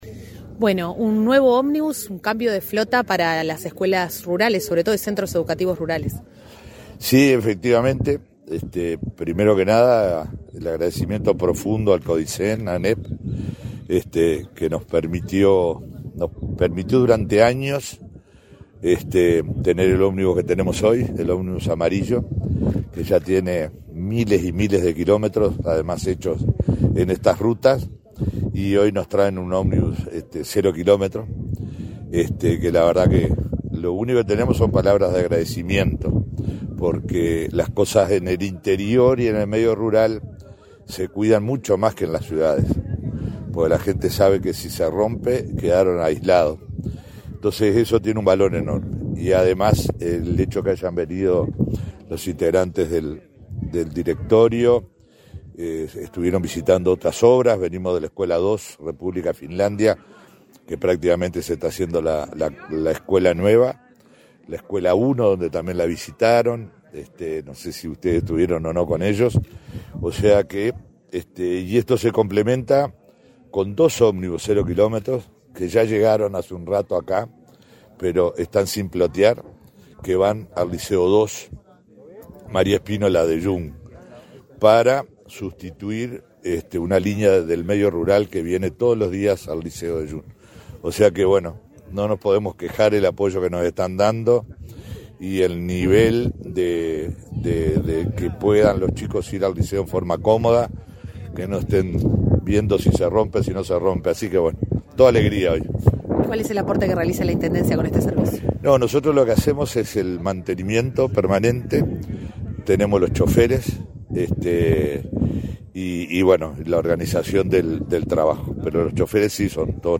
Entrevista al intendente de Río Negro, Omar Lafluf